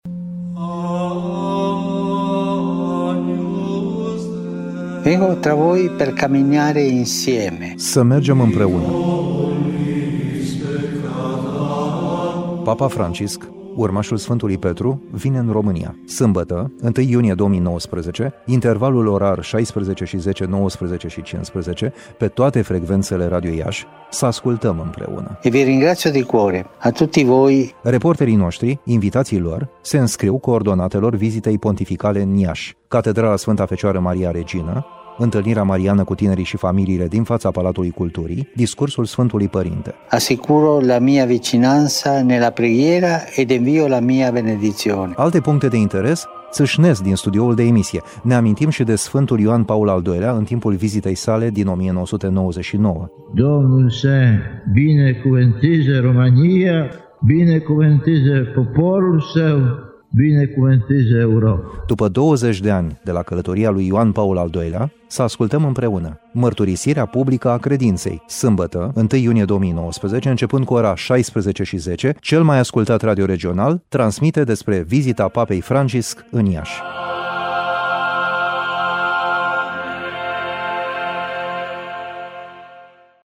Montaje audio
Teaser-VIZITA-PAPEI-FRANCISC-la-IASI.mp3